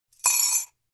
Эти натуральные аудиофрагменты можно использовать для создания атмосферы на кухне, ASMR-записей или звукового оформления видео.
Чайная ложка в стакан